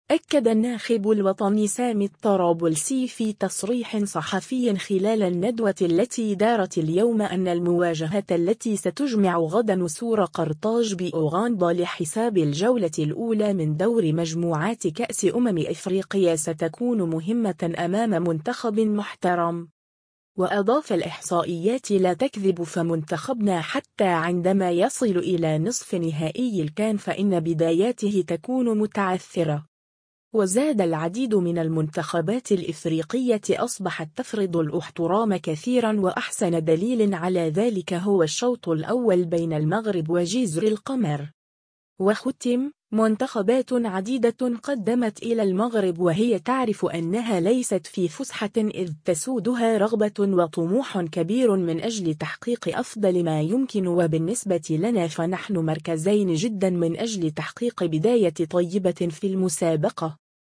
أكّد الناخب الوطني سامي الطرابلسي في تصريح صحفي خلال الندوة التي دارت اليوم أنّ المواجهة التي ستجمع غدا نسور قرطاج بأوغندا لحساب الجّولة الأولى من دور مجموعات كأس أمم إفريقيا ستكون مهمة أمام منتخب محترم.